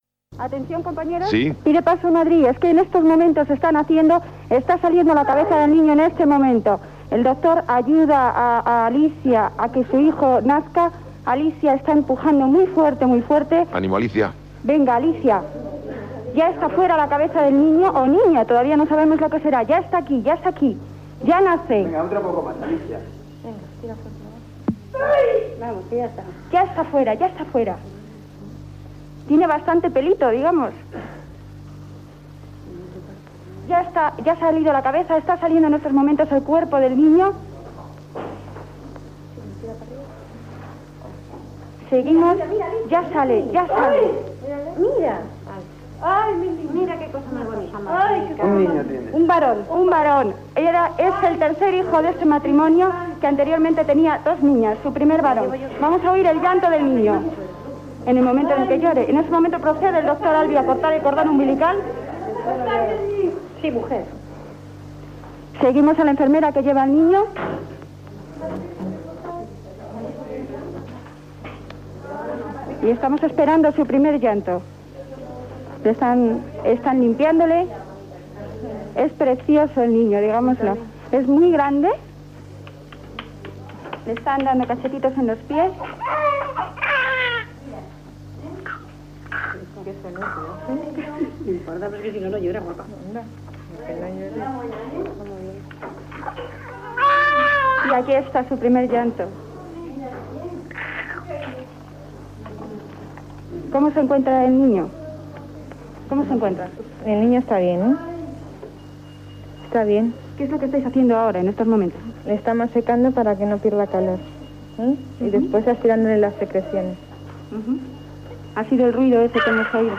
Transmissió del naixement d'un infant i del seu primer plor
Programa presentat per Iñaki Gabilondo.